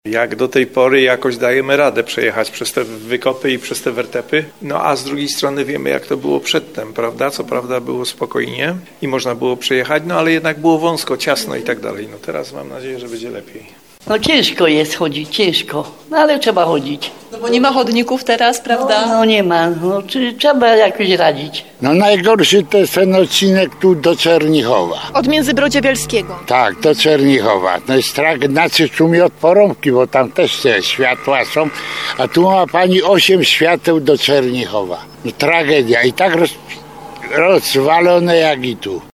To jest tragedia – komentuje sytuację na drodze mieszkaniec Krakowa, który do Czernichowa przyjeżdża do swojego domku letniskowego.